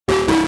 На этой странице представлена подборка звуков BIOS, включая редкие сигналы ошибок и системные оповещения.